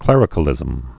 (klĕrĭ-kə-lĭzəm)